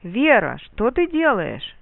Мелодическая схема обращения: